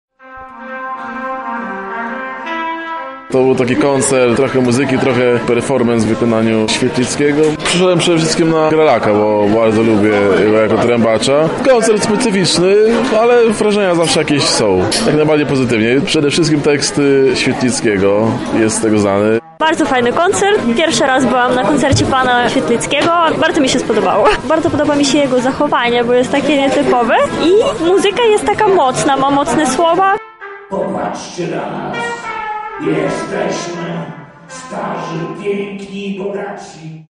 Relację z koncertu